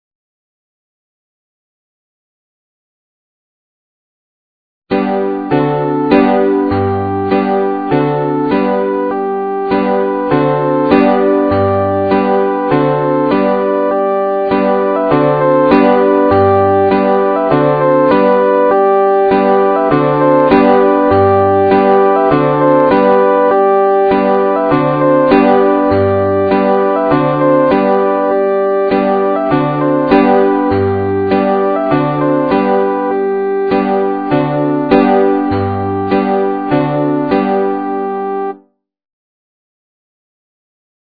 This ditty is meant to be sung as a round for four people.